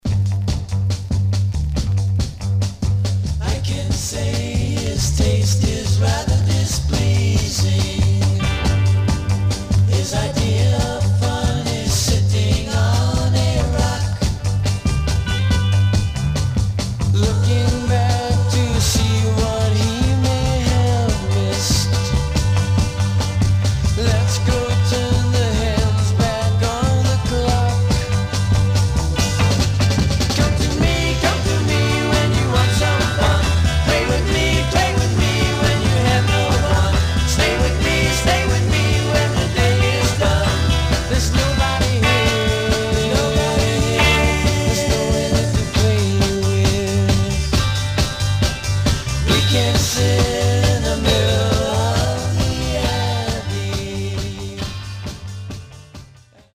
Some surface noise/wear Stereo/mono Mono
Garage, 60's Punk